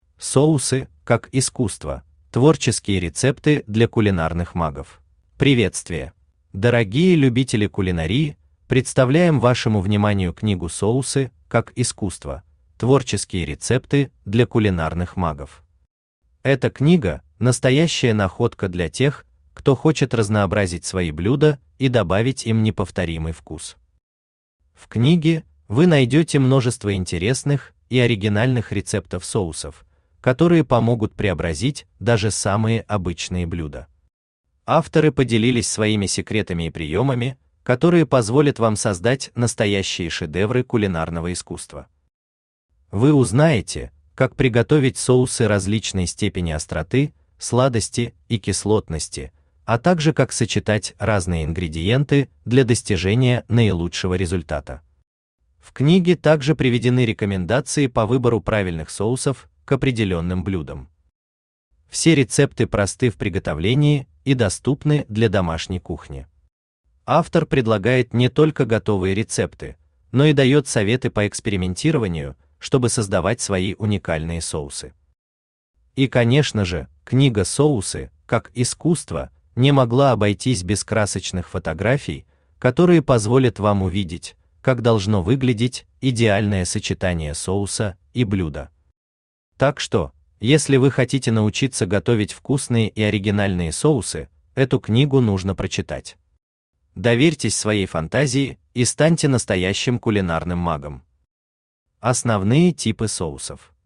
Аудиокнига Соусы, как искусство: творческие рецепты для кулинарных магов | Библиотека аудиокниг
Aудиокнига Соусы, как искусство: творческие рецепты для кулинарных магов Автор Архитектор Вкуса Читает аудиокнигу Авточтец ЛитРес.